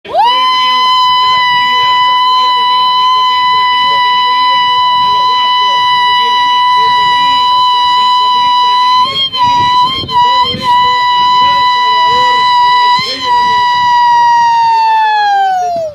grito-sapucai.mp3